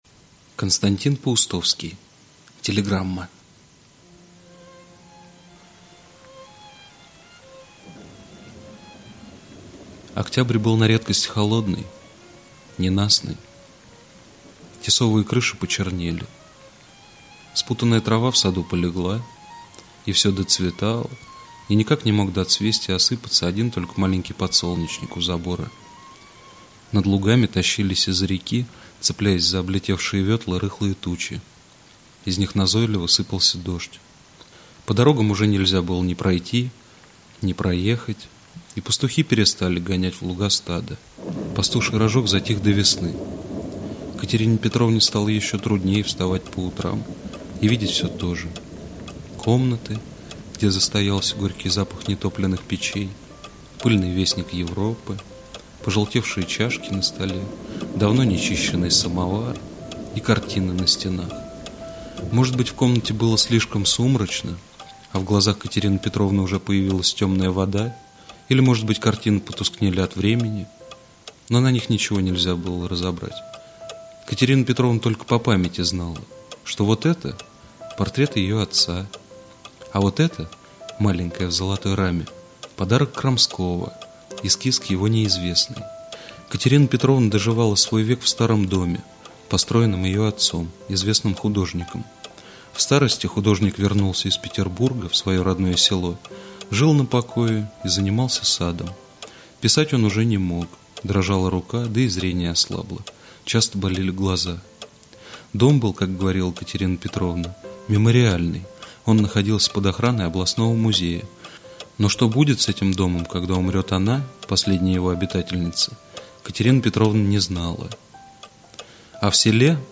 Телеграмма - аудио рассказ Паустовского - слушать онлайн